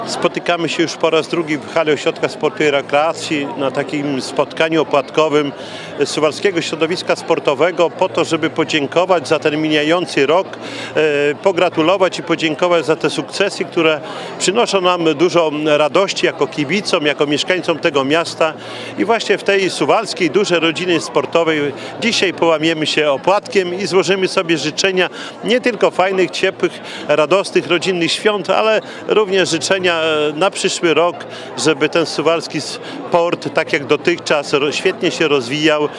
V Opłatek Sportowca odbył się w poniedziałek (17.12.18) w hali Ośrodka Sportu i Rekreacji w Suwałkach.